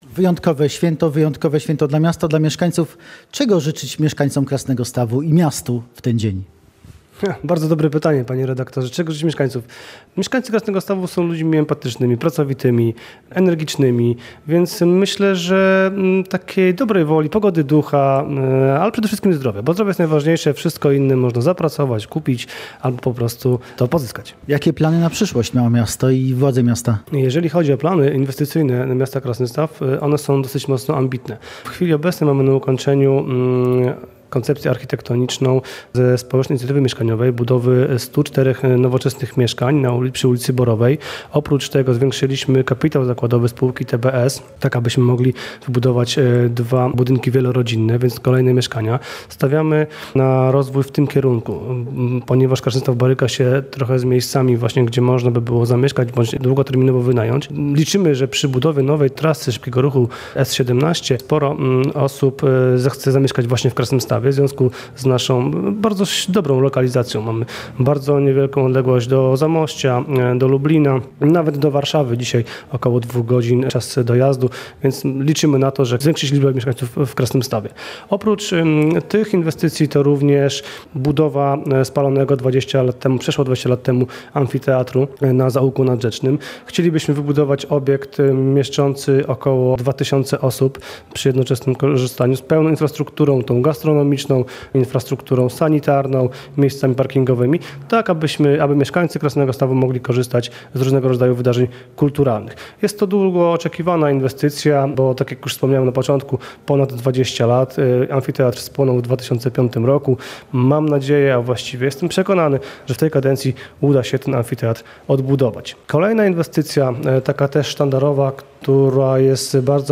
Rozmowa z burmistrzem Krasnegostawu Danielem Miciułą